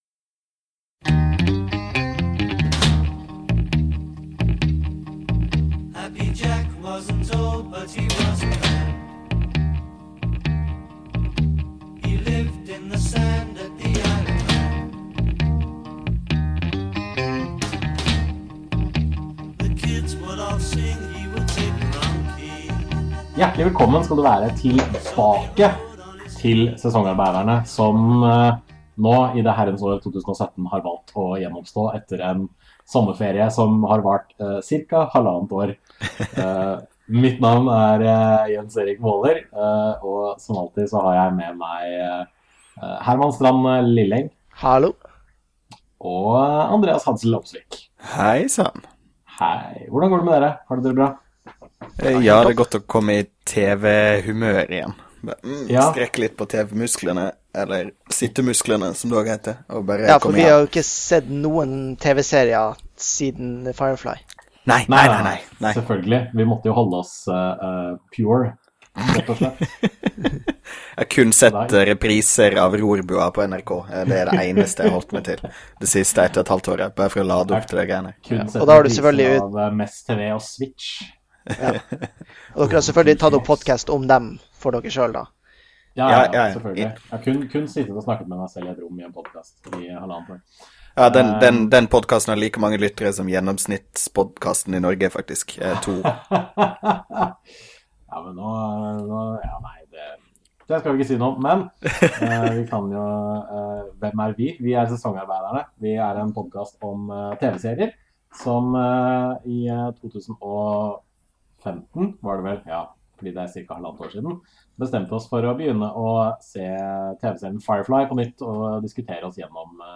PS: Vi beklager lydsurret i denne episoden